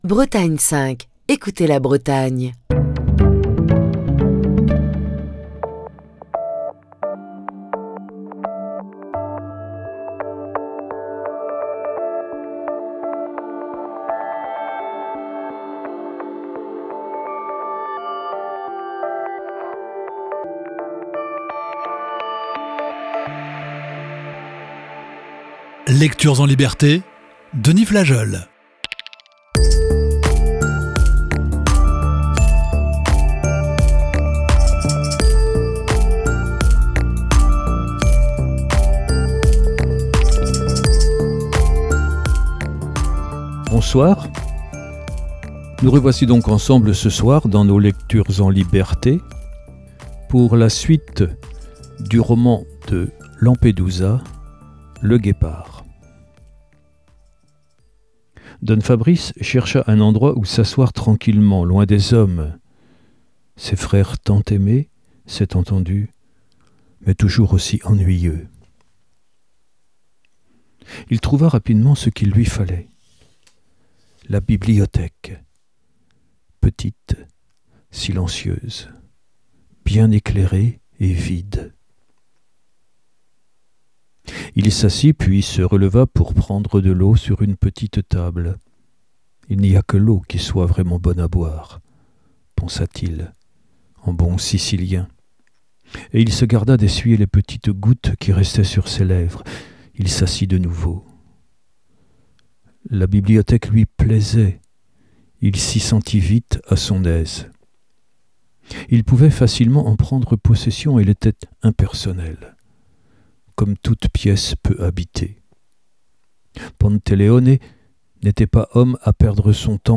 la lecture de la dernière partie du célèbre roman